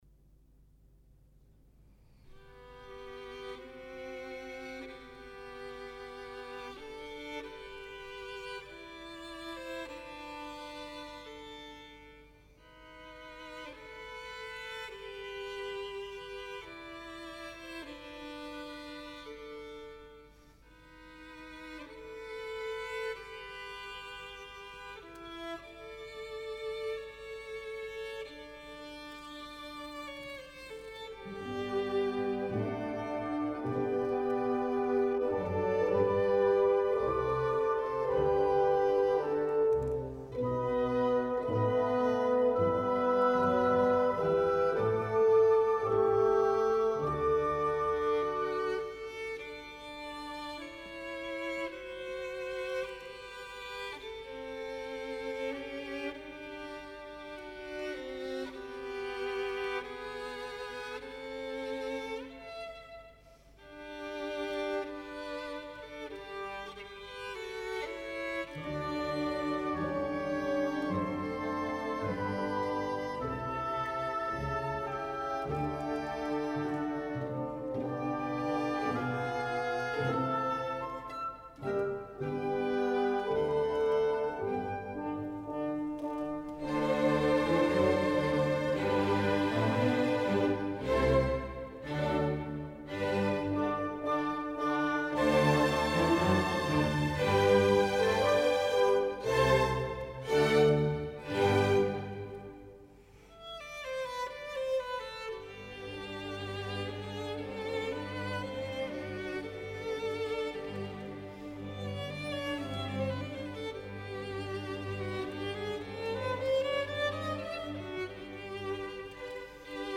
aus Konzert des Collegium musicum